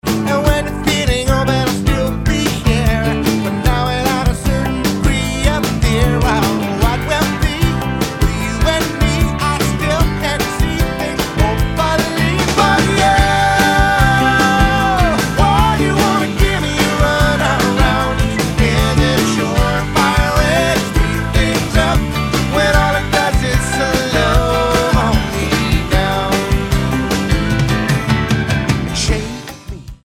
blues-rock dörtlüsü